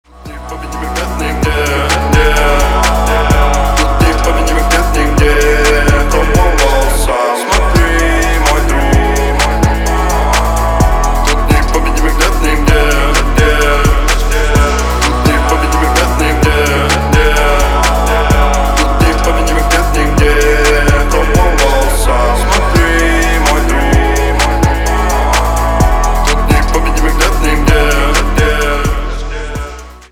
• Качество: 320, Stereo
Хип-хоп
русский рэп